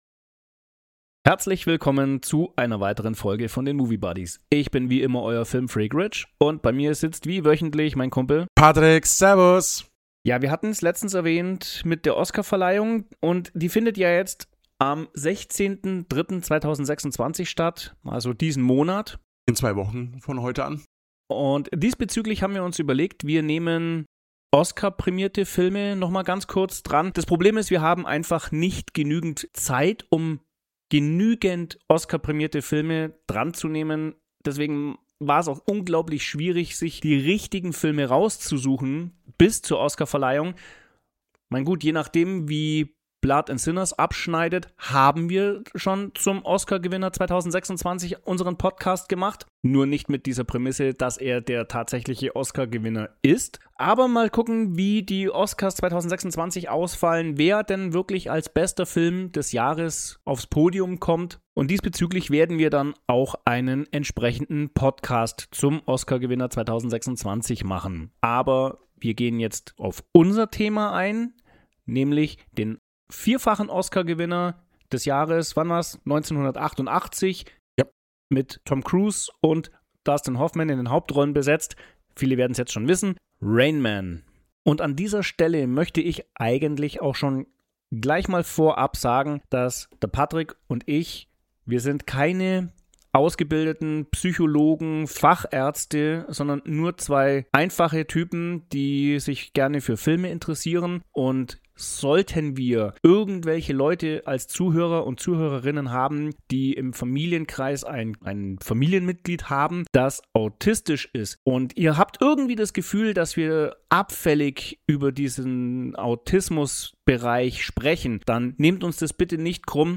Erlebt eine Unterhaltung über die Vorbereitungen Hoffmans auf seine Rolle, die Zusammenstellung der Rollen vor der Drehbuchüberarbeitung, die Handlung des Film und einiges mehr.